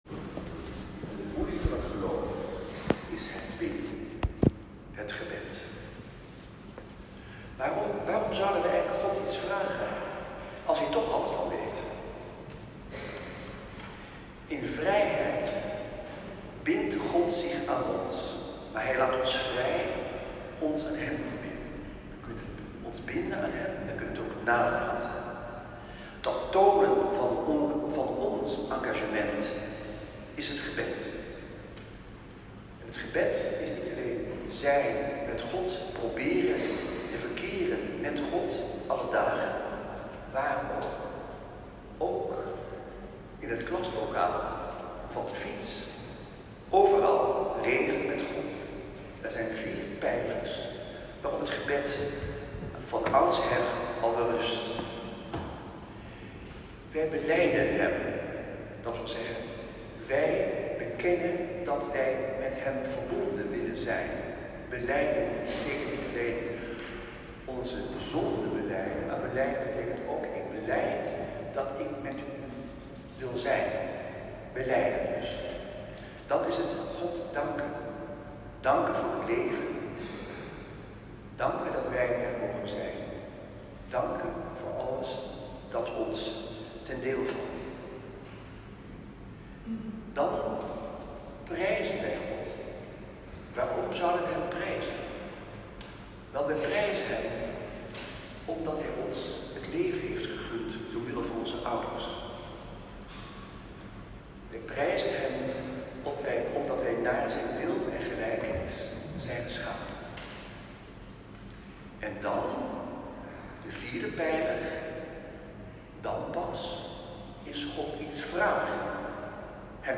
Preek.m4a